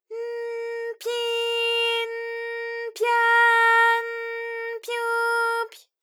ALYS-DB-001-JPN - First Japanese UTAU vocal library of ALYS.
py_n_pyi_n_pya_n_pyu_py.wav